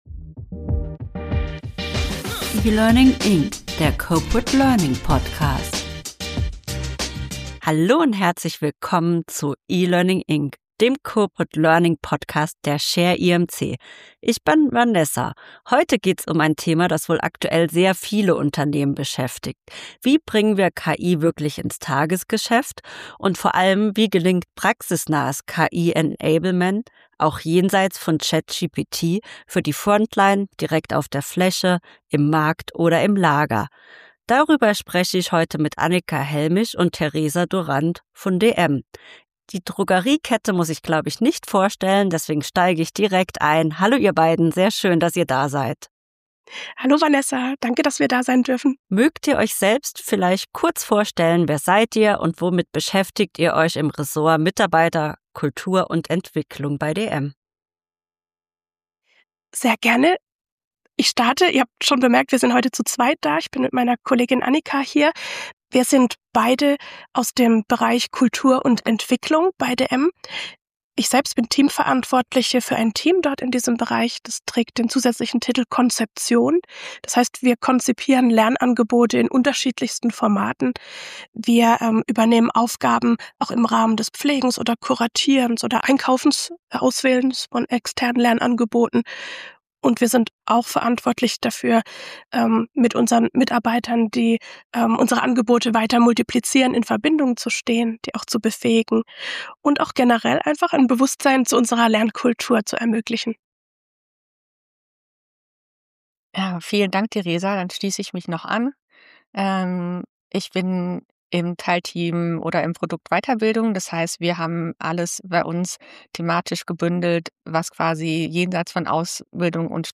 E-Learning Experten im Interview